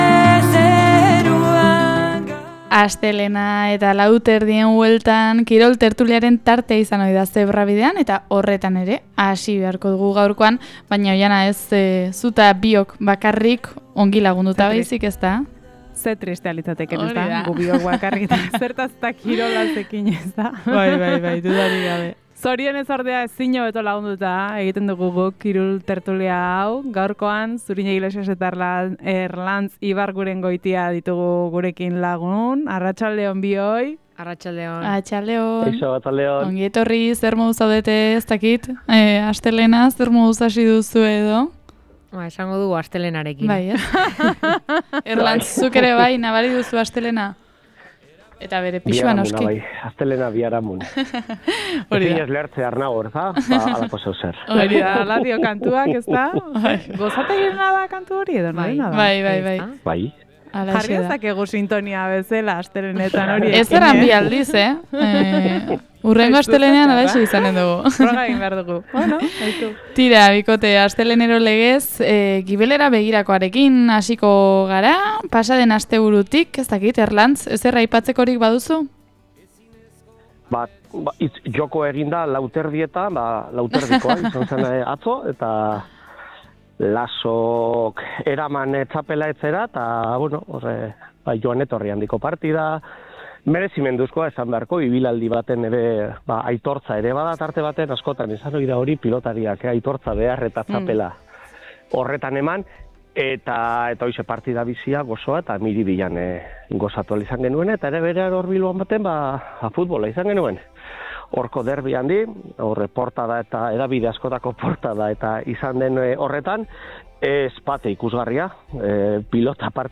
kirol tertulia